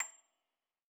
53l-pno29-A6.wav